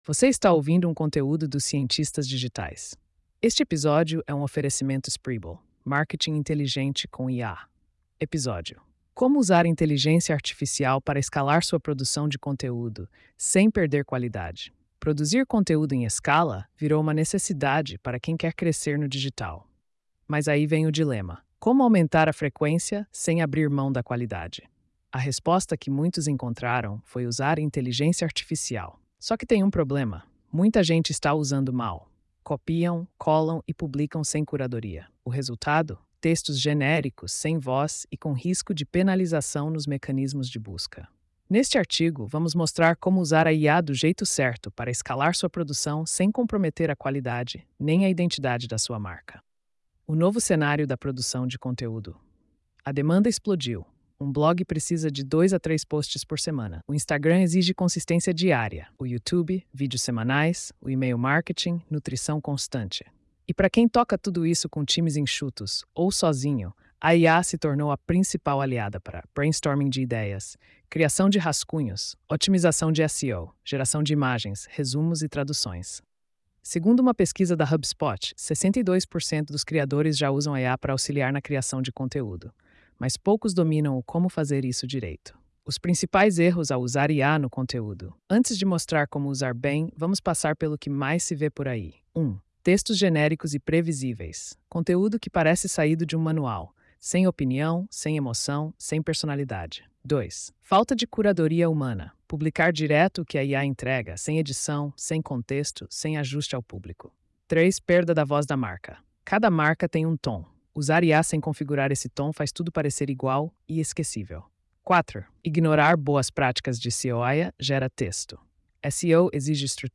post-4124-tts.mp3